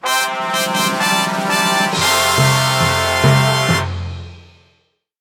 11550 grand prix brass fanfare
brass fanfare grand-prix medalist royal trumpets win winner sound effect free sound royalty free Memes